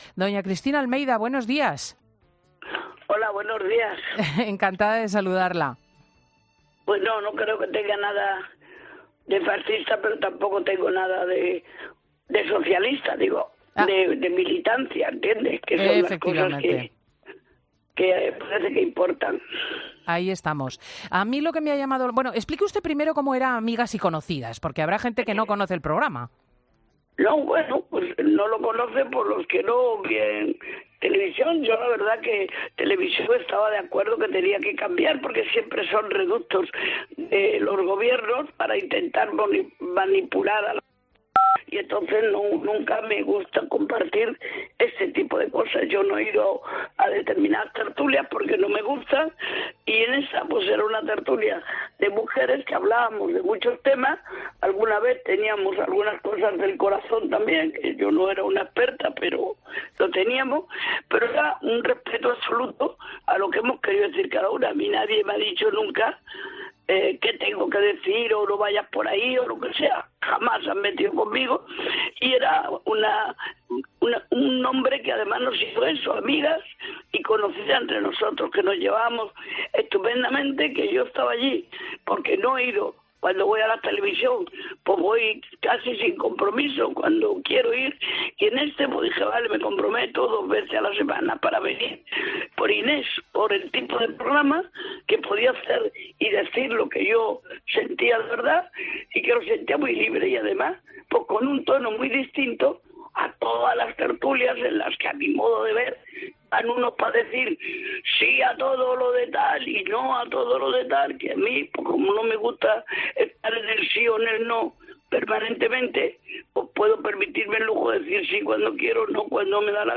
Su mensaje en el programa 'Fin de semana COPE' es claro: "Me da pena.